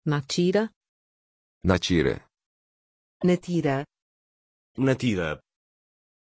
Pronunciation of Natyra
pronunciation-natyra-pt.mp3